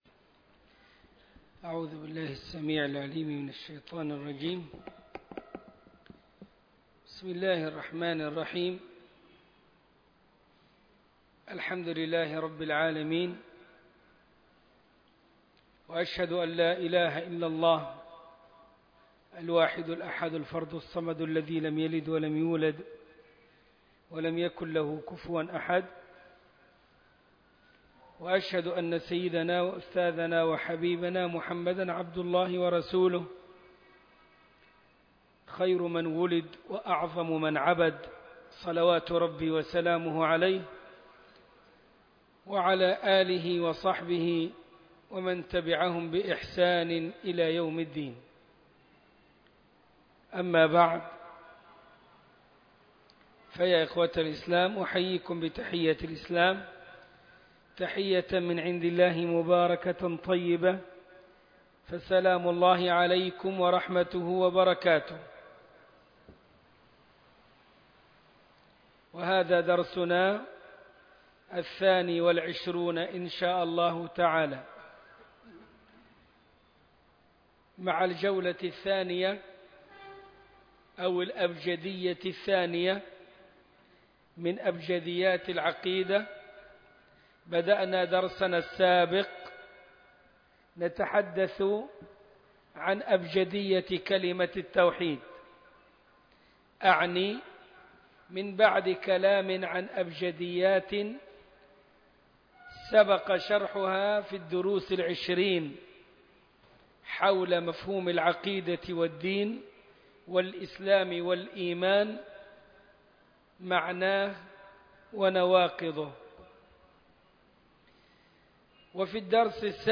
عنوان المادة المحاضرة الثانية والعشرون (22) تاريخ التحميل الأربعاء 30 ديسمبر 2020 مـ حجم المادة 29.89 ميجا بايت عدد الزيارات 276 زيارة عدد مرات الحفظ 132 مرة إستماع المادة حفظ المادة اضف تعليقك أرسل لصديق